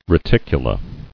[re·tic·u·la]